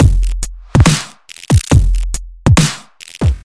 Звуки битовой музыки
Здесь вы найдете мощные ритмы, минималистичные мелодии и экспериментальные звуковые текстуры.
Дабстеп